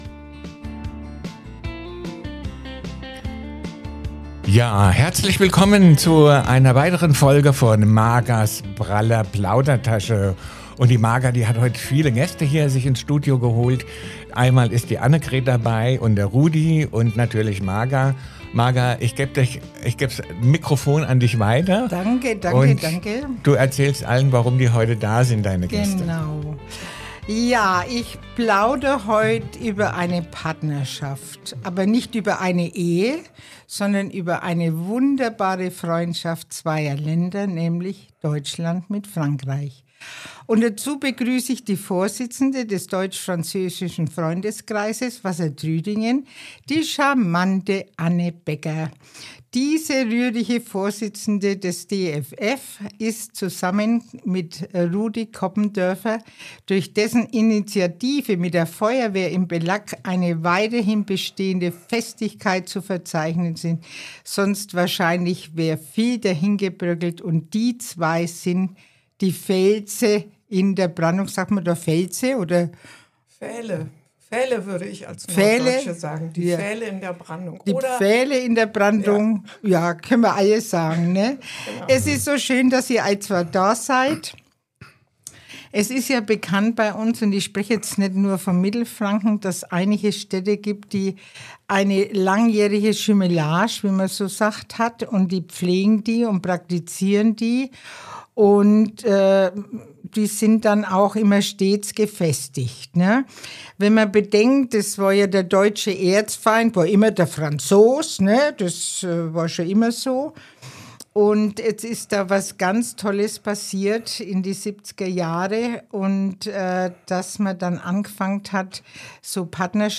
In dieser Folge spreche ich mit meinen Gästen